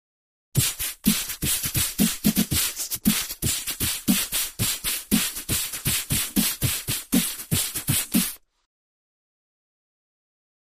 Human Beat Box, Aggressive Beat, Type 2